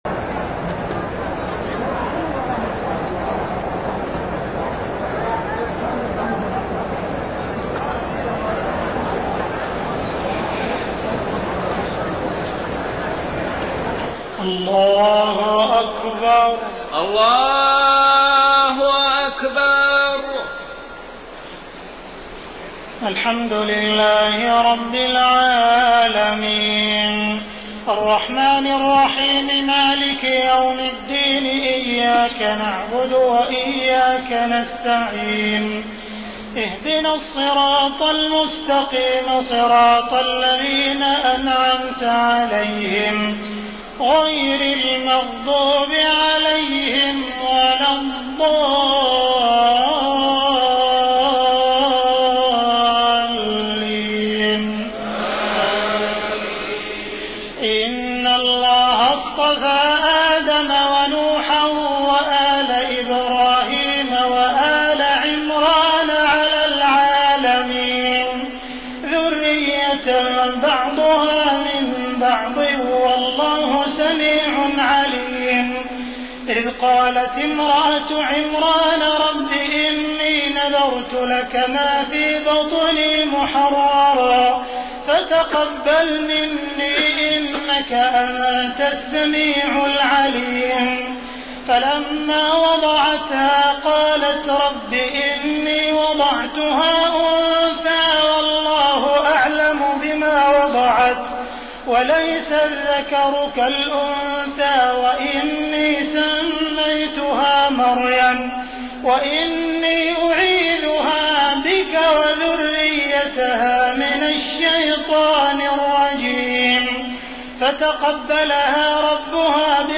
تهجد ليلة 23 رمضان 1433هـ من سورة آل عمران (33-92) Tahajjud 23 st night Ramadan 1433H from Surah Aal-i-Imraan > تراويح الحرم المكي عام 1433 🕋 > التراويح - تلاوات الحرمين